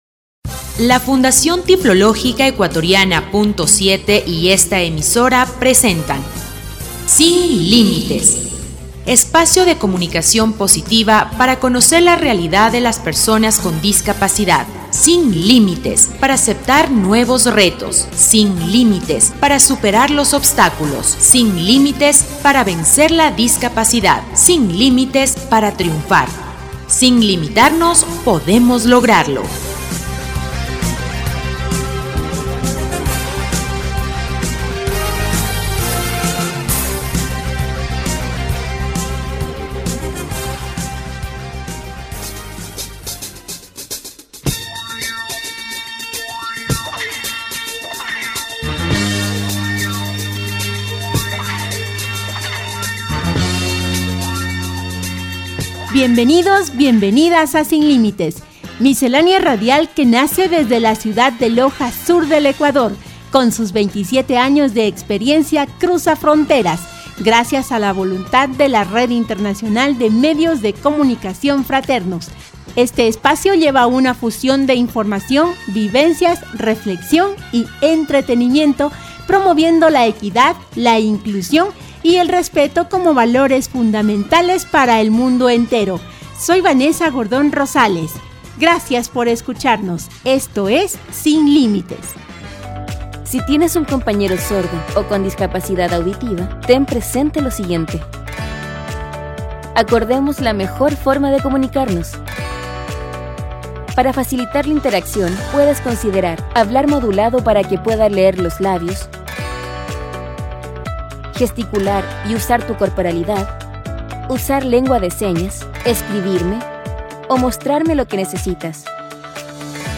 Programa radial «Sin Límites» 1371